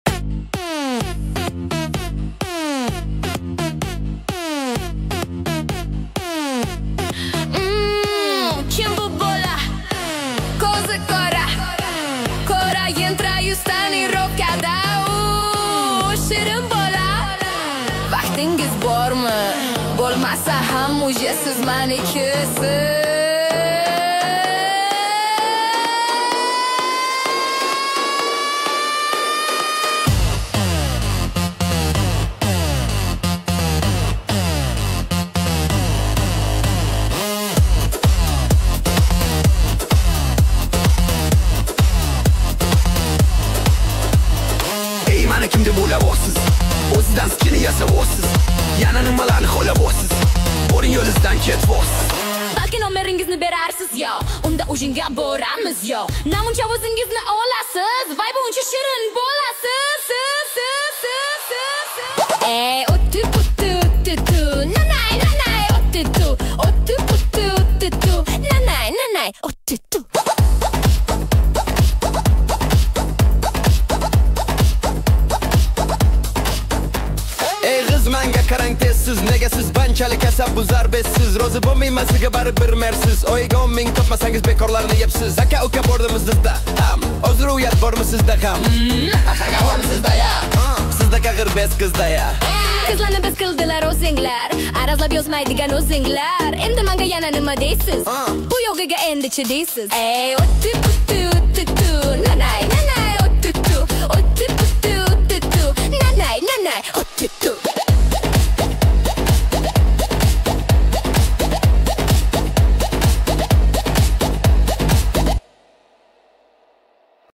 Узнайте текст и наслаждайтесь танцевальным ритмом!